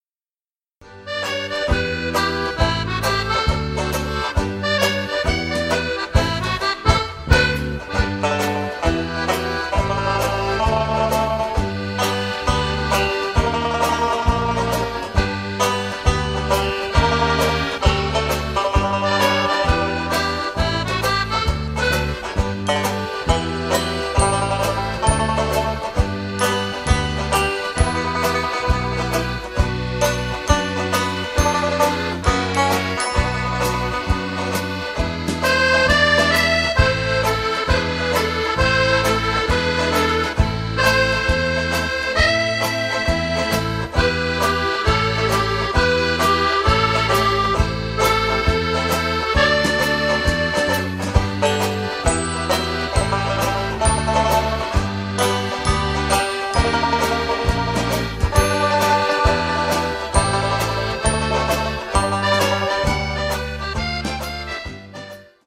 8-beat intro.
Verse is in the key of Em, Chorus is in G.